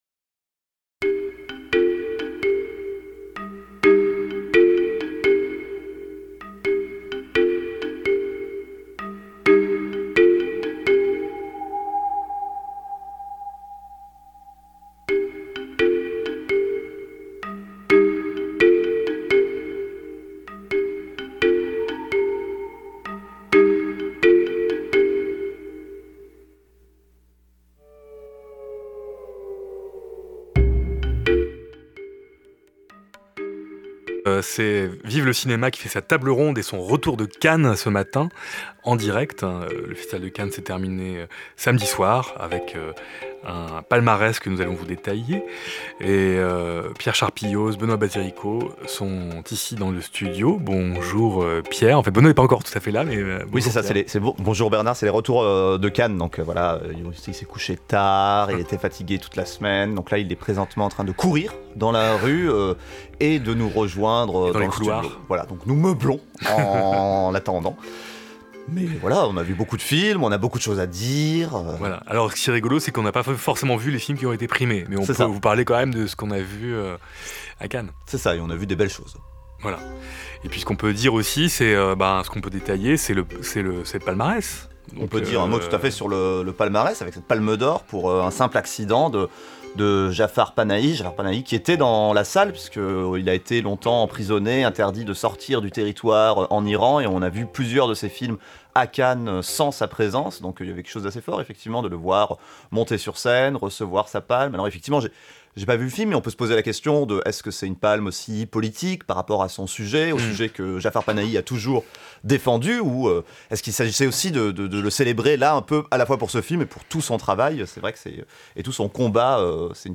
Vive Le Cinéma #26 mai 2025 : Table Ronde Critique - Retour sur le Festival de Cannes